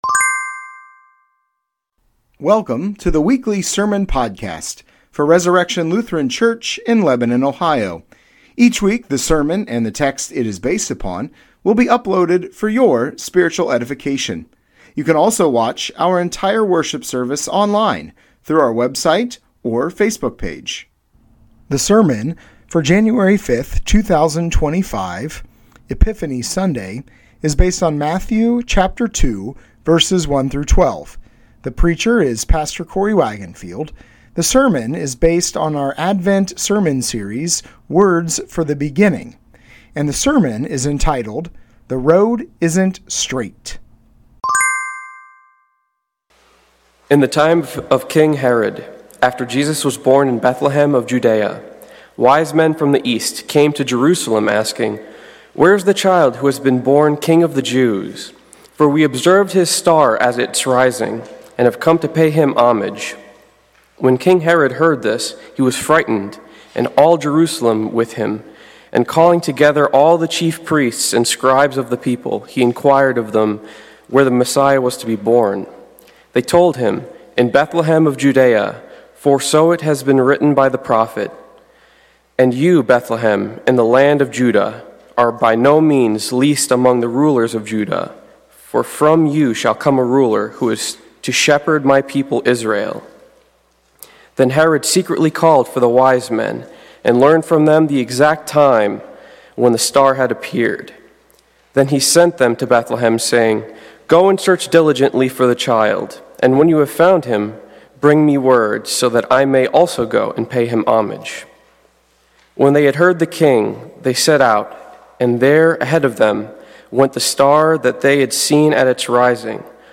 Sermons | Resurrection Lutheran Church